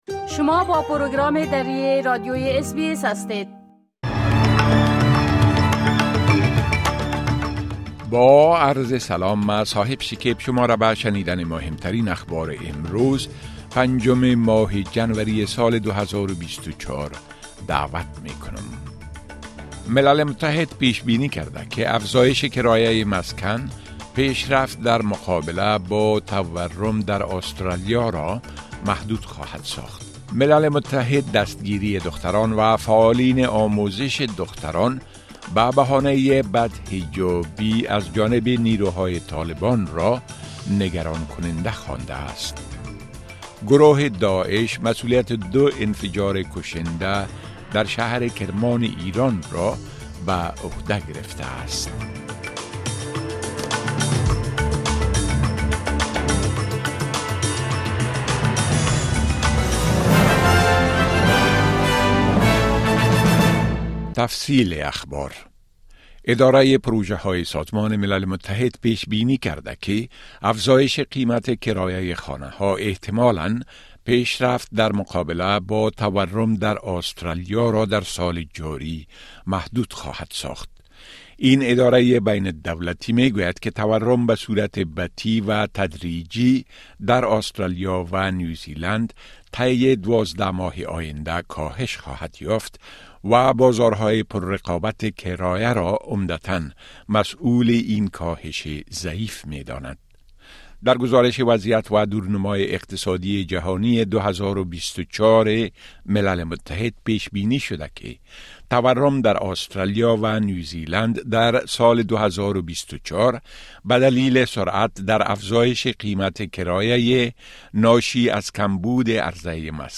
گزارش رويدادهاى مهم اخير از برنامۀ درى راديوى اس بى اس